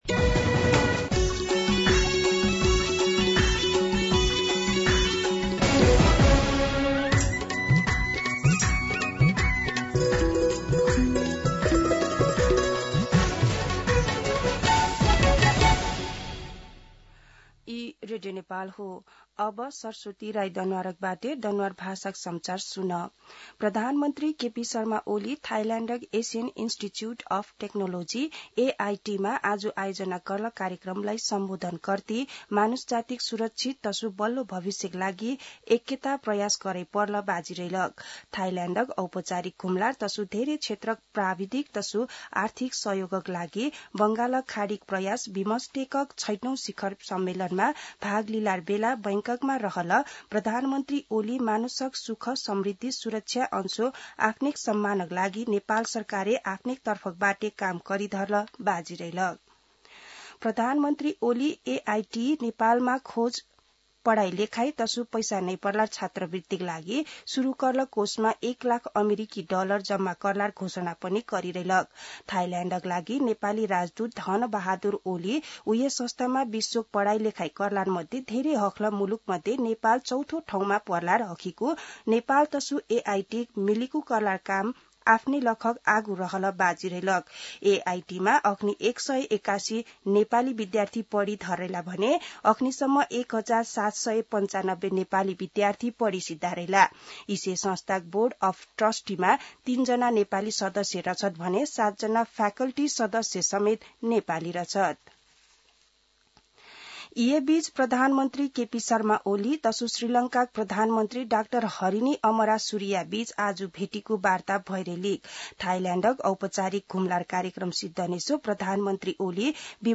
दनुवार भाषामा समाचार : २१ चैत , २०८१
Danuwar-News.mp3